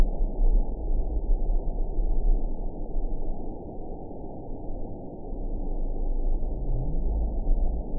event 922704 date 03/15/25 time 06:10:17 GMT (1 month, 2 weeks ago) score 6.07 location TSS-AB10 detected by nrw target species NRW annotations +NRW Spectrogram: Frequency (kHz) vs. Time (s) audio not available .wav